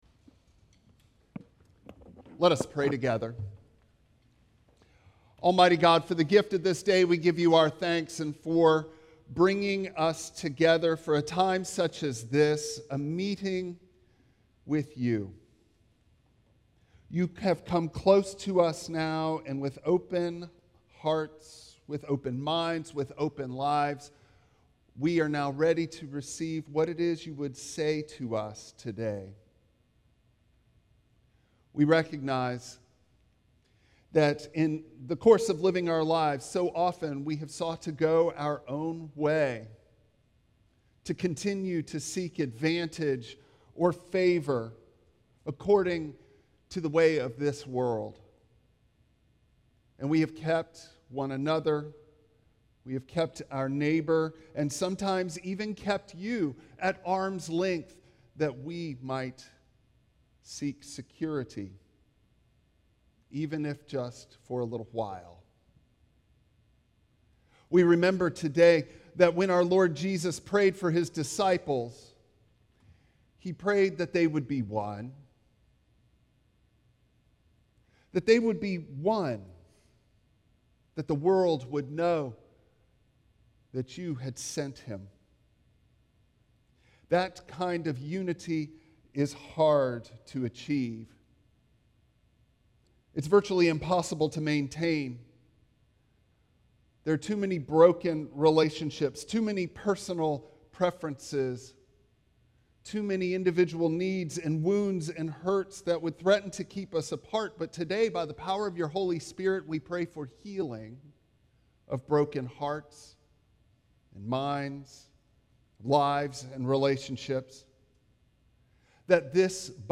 Done in translation with a guest Burmese pastor.
Galatians 3:26-29 Service Type: Traditional Service Bible Text